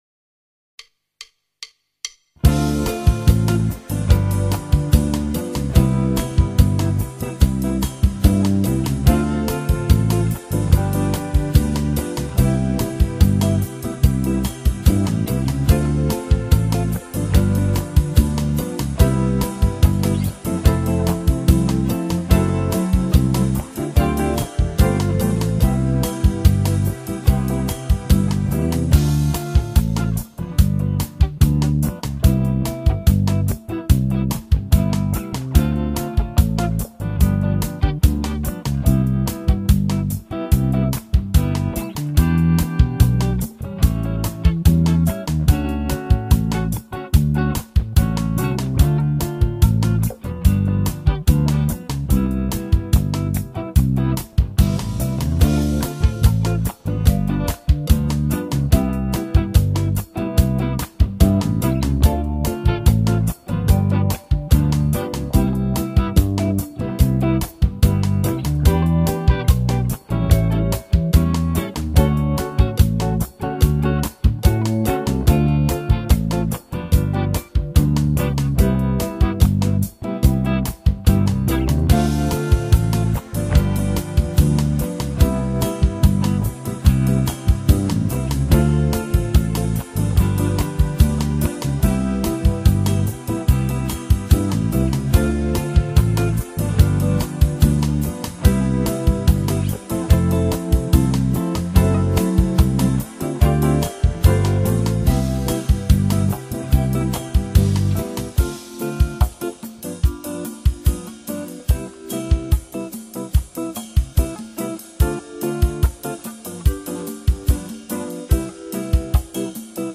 II V I Bossa nova Backing Track in C.